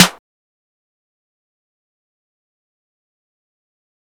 Tm8_Clap27.wav